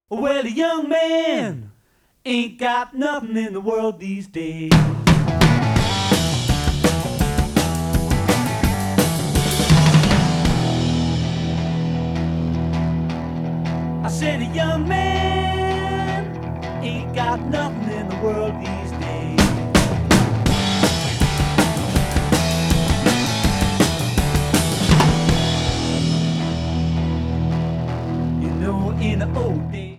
08) Original version, remixed and remastered.